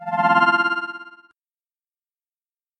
Оповещение о подписке звук в стриме